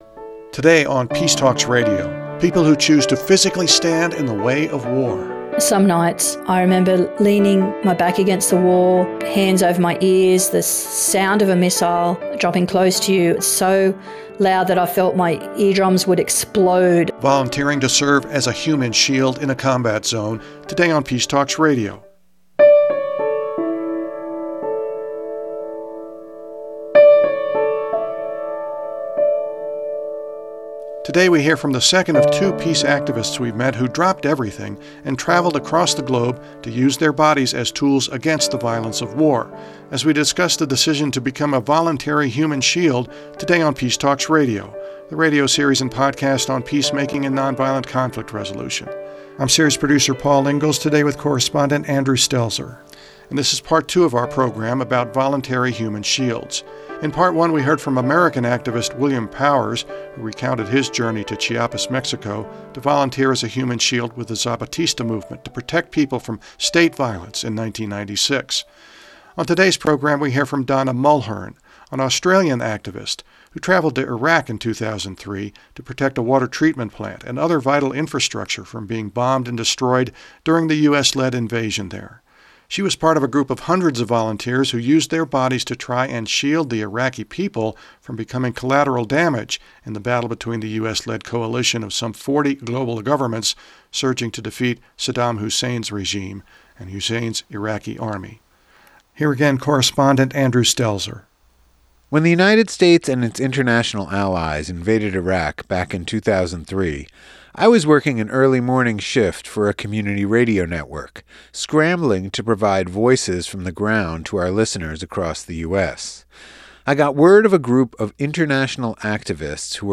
On this edition of Peace Talks Radio, we hear from people who chose to physically stand in the way of war. Volunteering to serve as a “human shield” is an intense act of self-sacrifice.